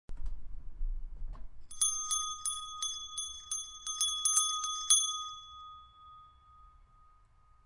复古铃铛和蜂鸣器 " G2027电话铃铛
描述：老式电话铃响。 这些是20世纪30年代和20世纪30年代原始硝酸盐光学好莱坞声音效果的高质量副本。
我已将它们数字化以便保存，但它们尚未恢复并且有一些噪音。
标签： 贝尔 电话 戒指 眼镜 复古
声道立体声